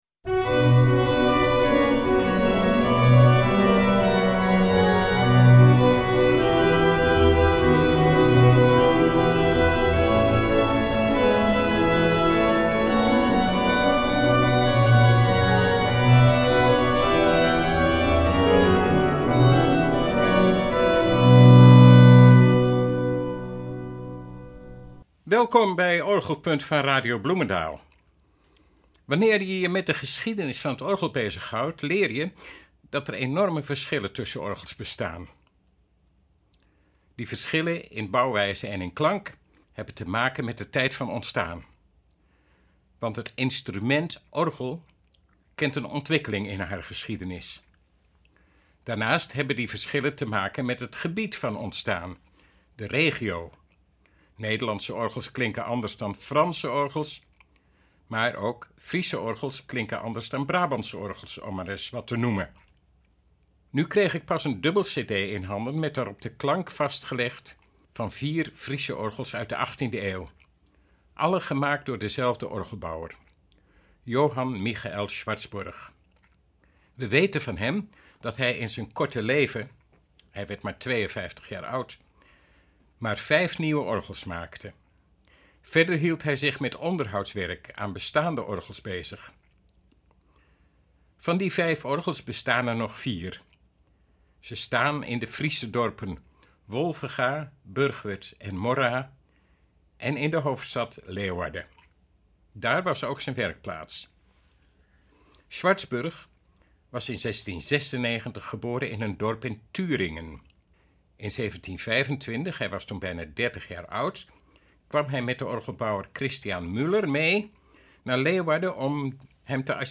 De klank is kleurrijk en briljant.